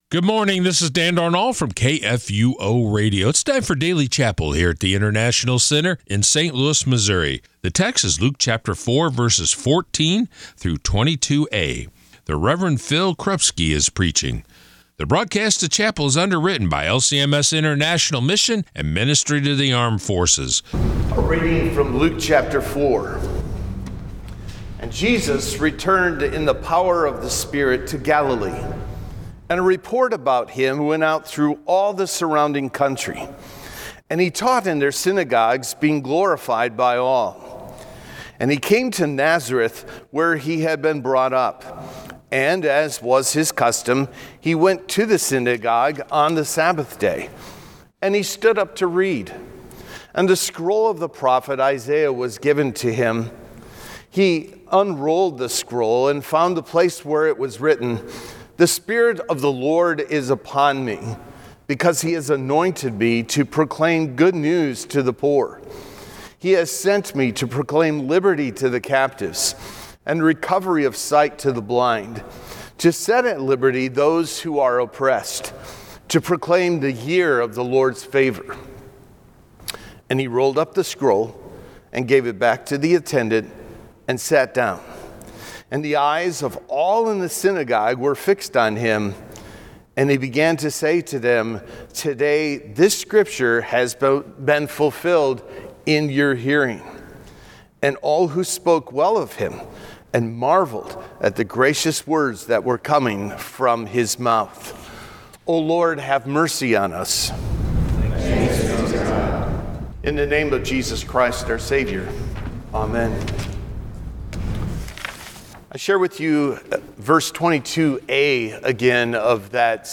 Daily Chapel